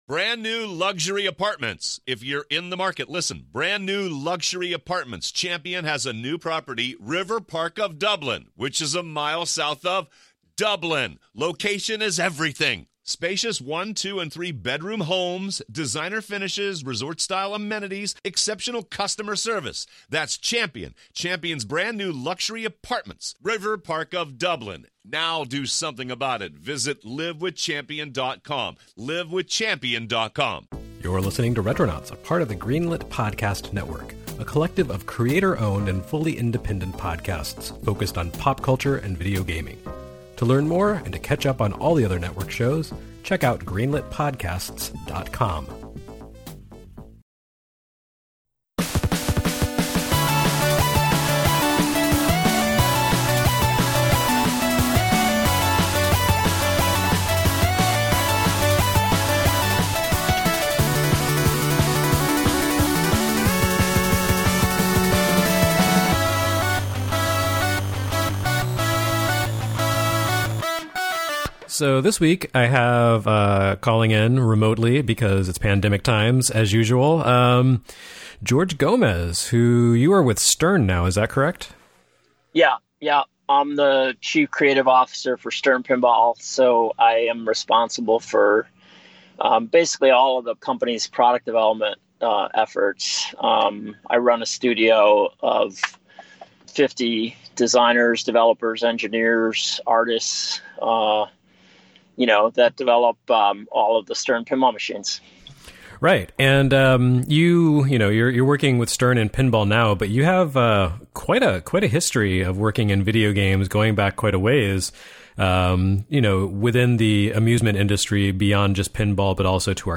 chats one-on-one with an arcade gaming legend